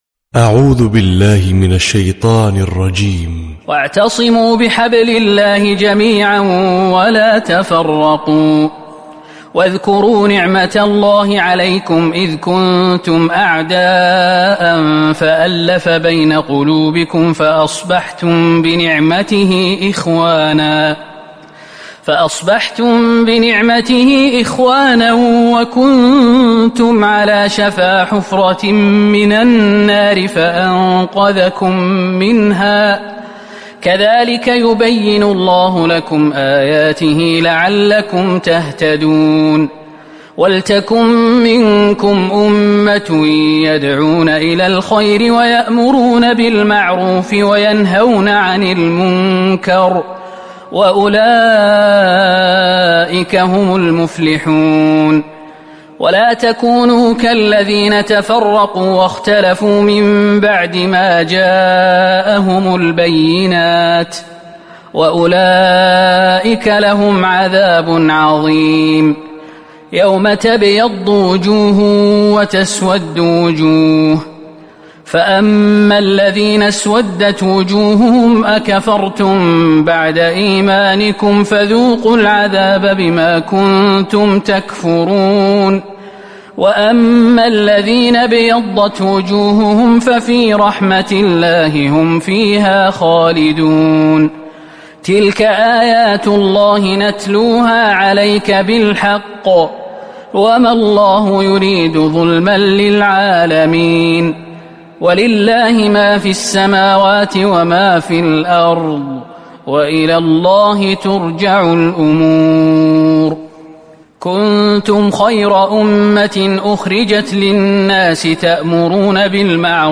🕋🌻•تلاوة صباحية•🌻🕋